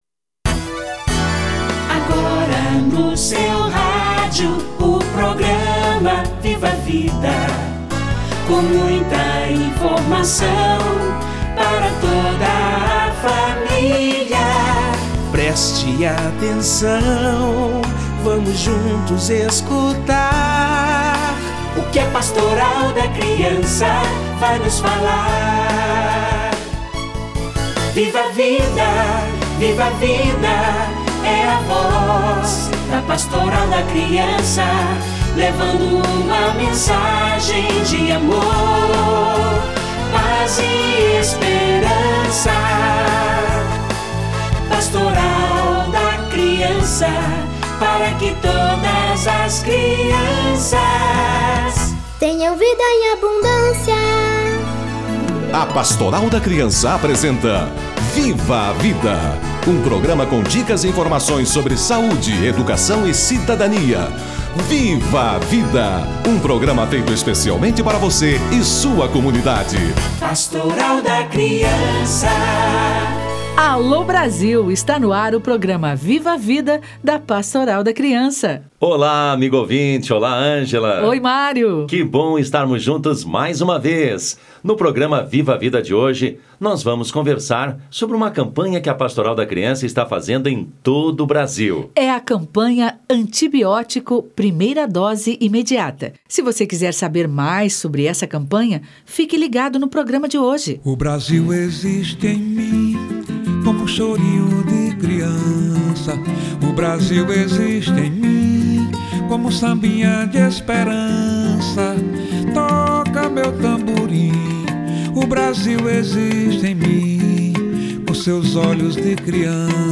Antibiótico: primeira dose imediata - Entrevista